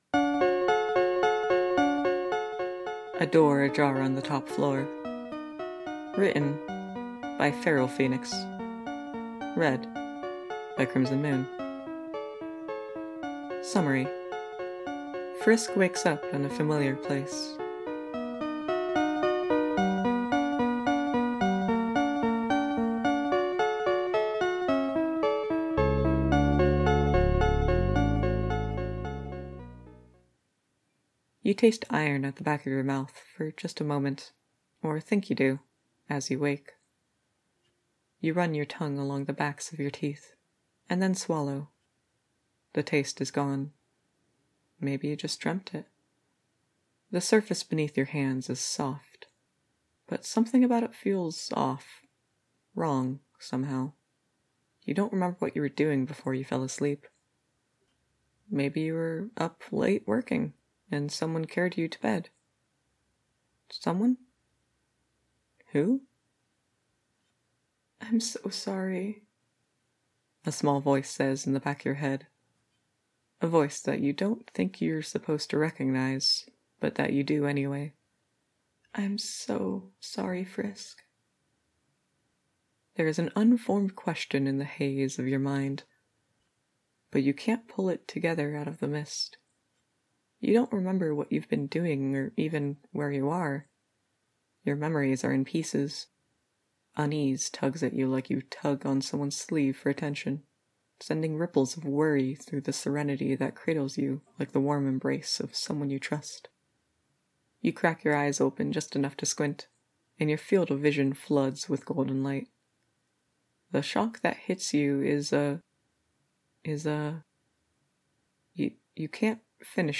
collaboration|two voices